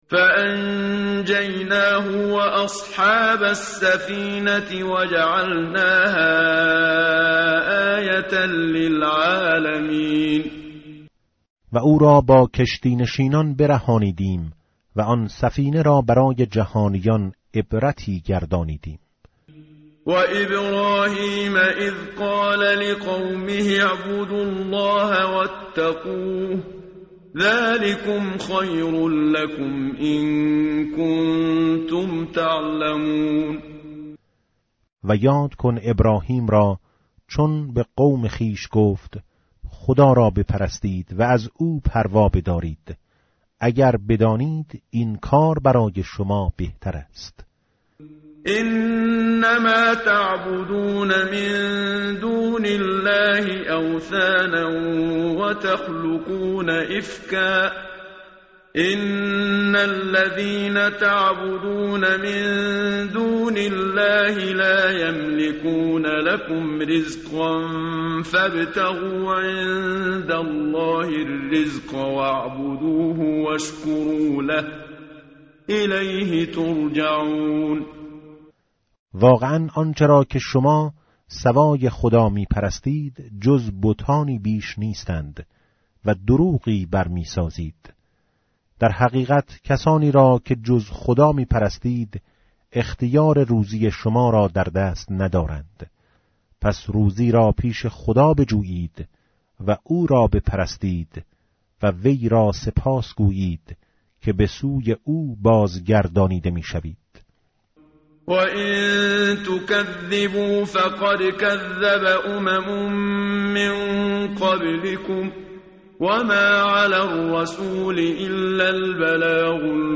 tartil_menshavi va tarjome_Page_398.mp3